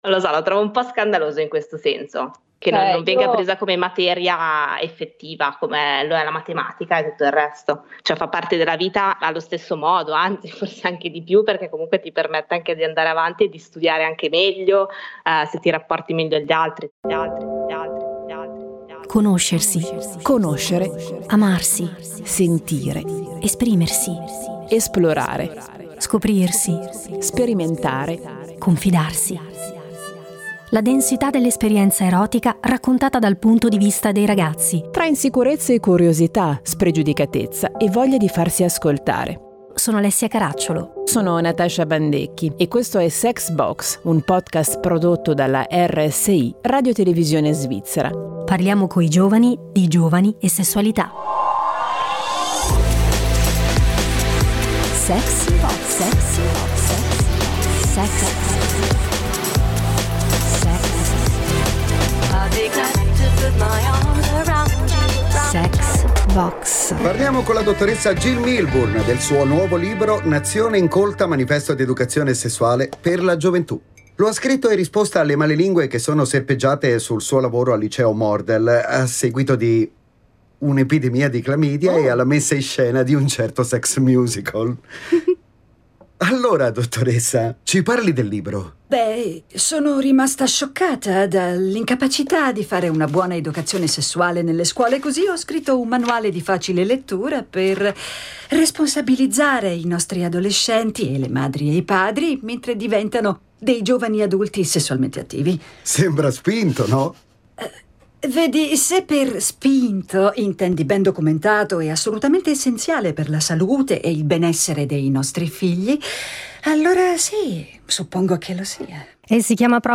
In questo episodio i ragazzi raccontano esperienze personali ed aspettative rispetto all’educazione sessuale, nella creazione del loro bagaglio di informazioni e di un’idea sensata e responsabile di sessualità.